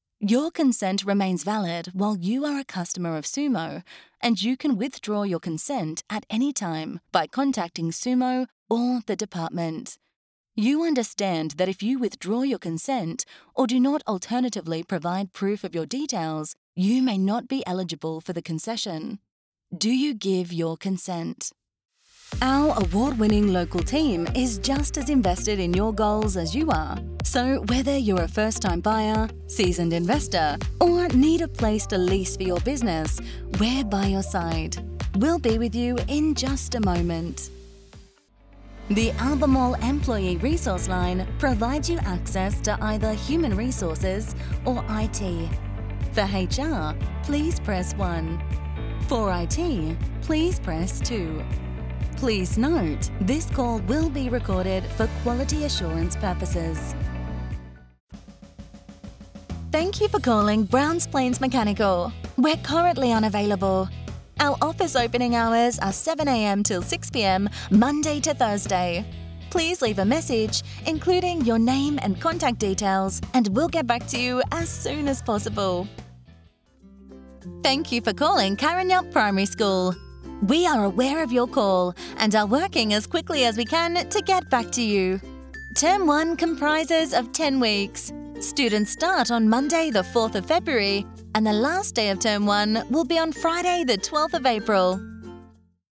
Commercial, Playful, Versatile
Telephony